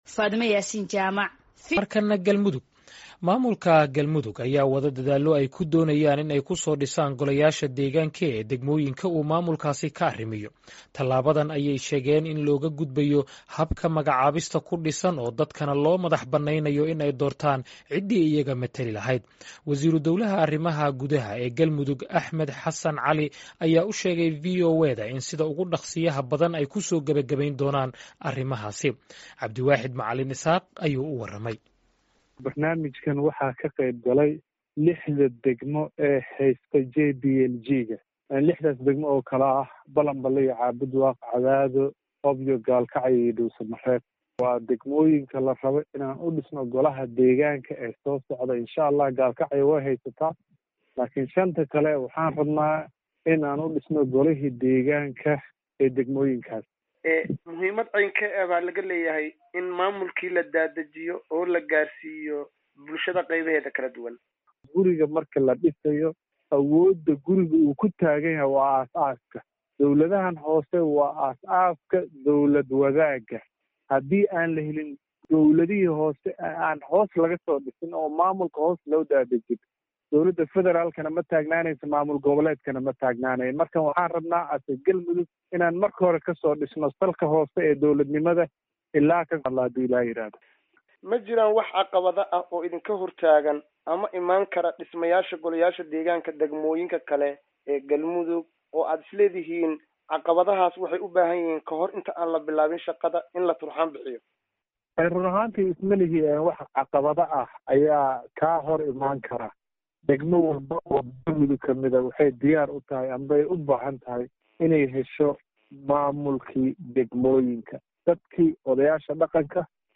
Wasiiru dowlaha arrimaha gudaha ee Galmudug Axmed Xasan Cali ayaa u sheegay VOA-da in sida ugu dhakhsiyaha badan ay kusoo gabagabeyn doonaan.
Wareysi: Dhismaha goleyaasha deegaanka ee degmooyinka Galmudug